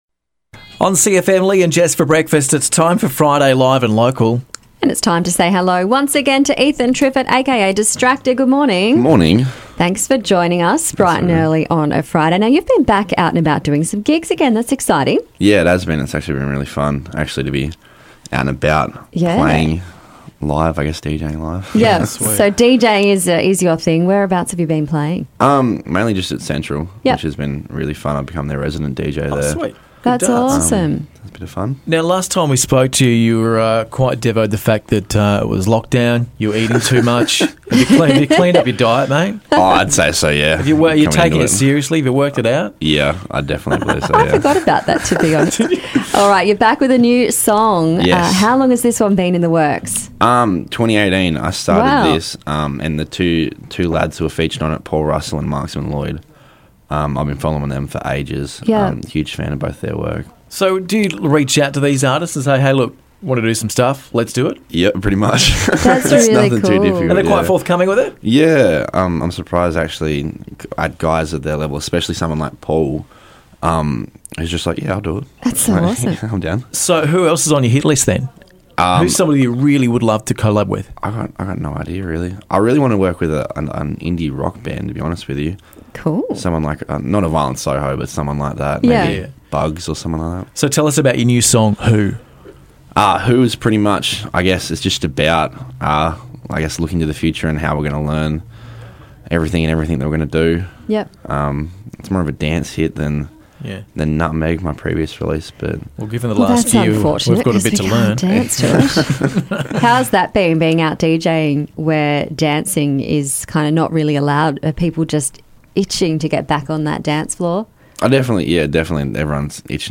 Local musician